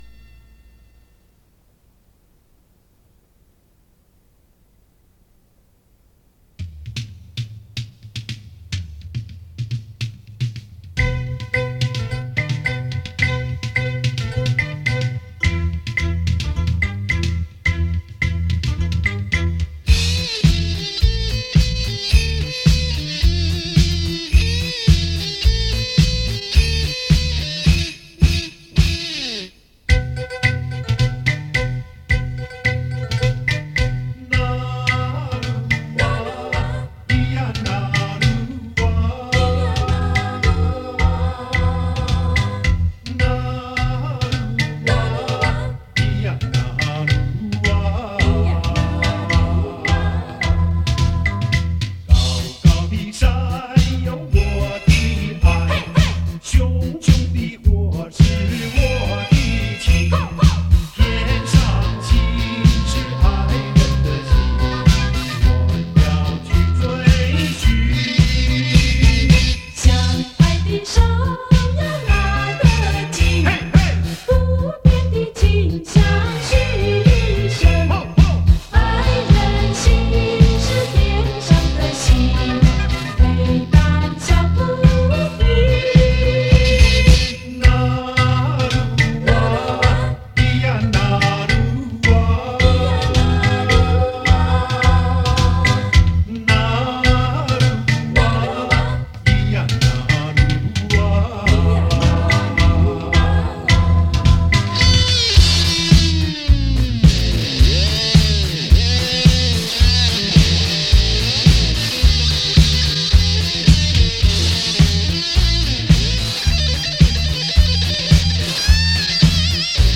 大制作·特殊唱法·百听不厌